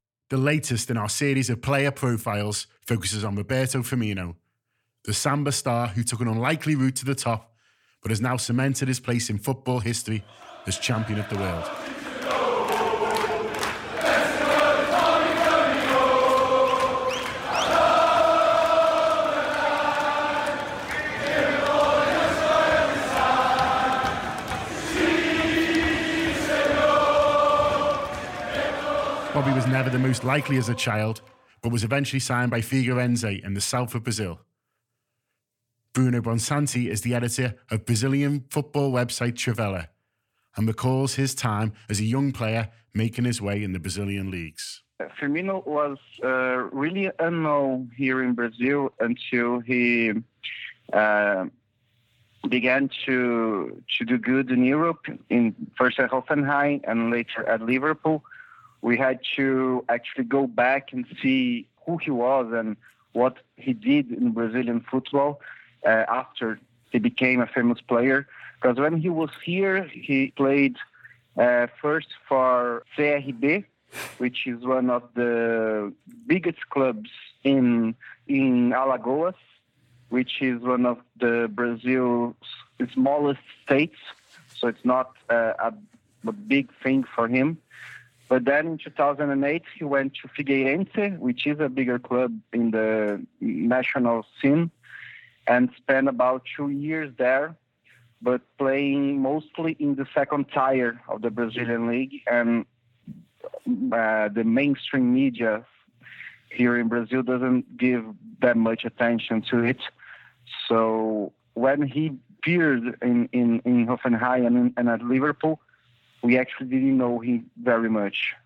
The second in a series of audio documentaries in which we delve into the backgrounds of members of the current squad, tracing back Roberto Firmino’s journey from unlikely Samba Star to World Champion…